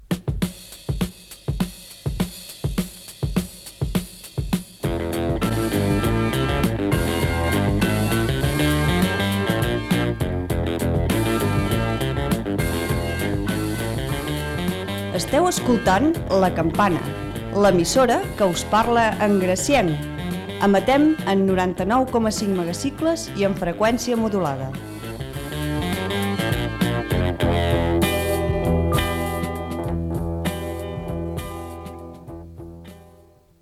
Indicatiu "L'emissora que us parla en gracienc".
FM